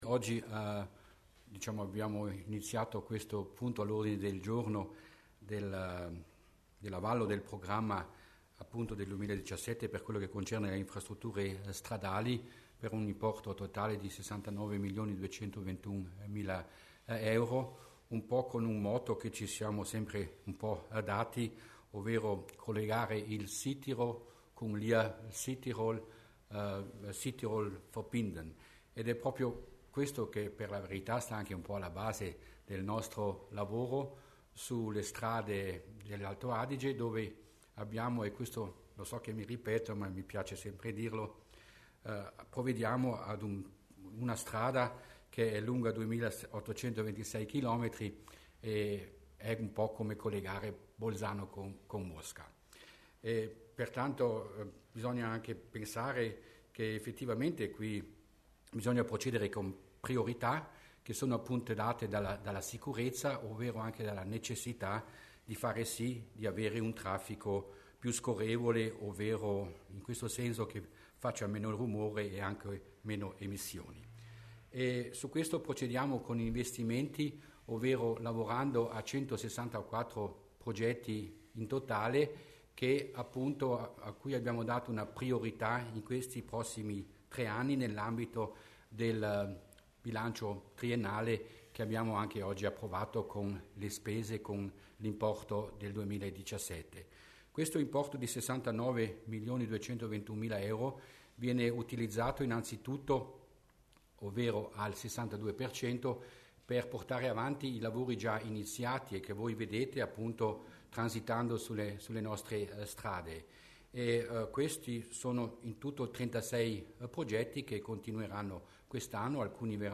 L'Assessore Mussner spiega gli investimenti in infrastrutture stradali